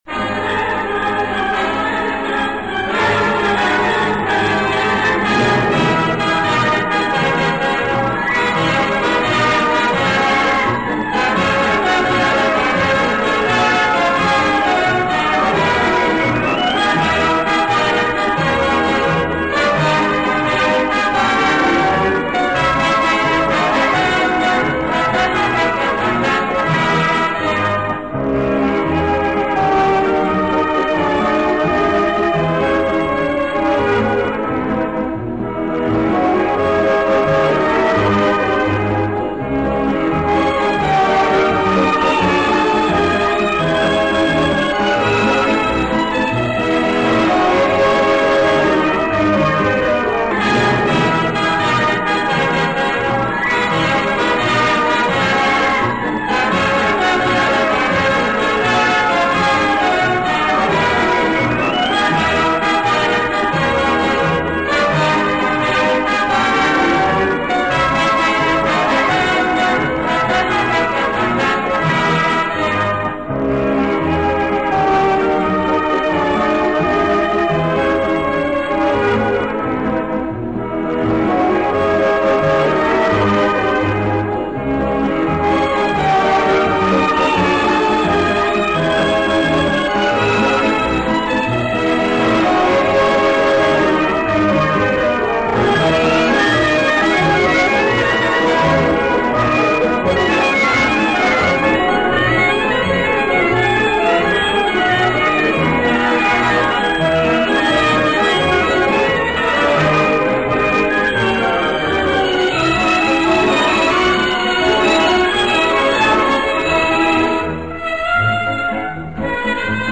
Вальс смонтирован отлично.